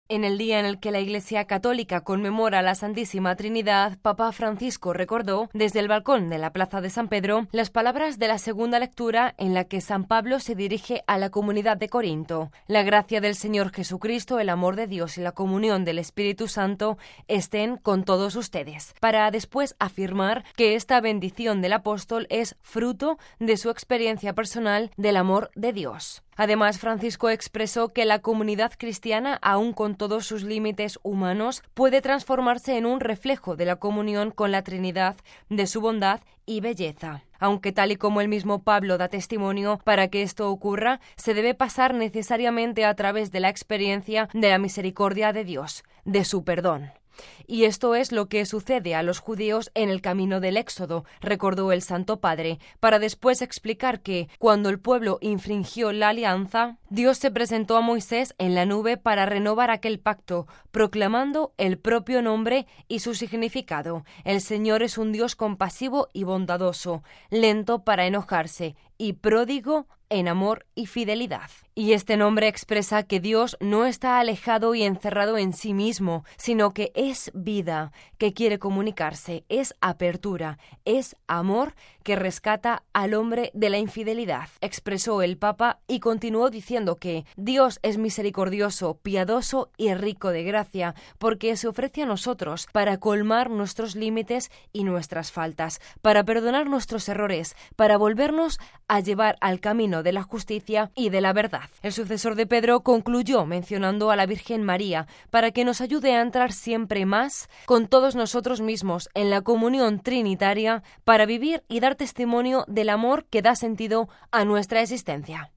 (RV).- En el día en el que la Iglesia Católica conmemora a la Santísima Trinidad, Papa Francisco recordó, desde el Balcón de la Plaza de San Pedro, las palabras de la segunda lectura en la que San Pablo se dirige a la comunidad de Corinto “La gracia del Señor Jesucristo, el amor de Dios y la comunión del Espíritu Santo estén con todos ustedes"(2 Cor 13,13), para después afirmar que esta “bendición” del Apóstol es fruto de su experiencia personal del amor de Dios.
Reflexión del Papa antes de la oración del Ángelus: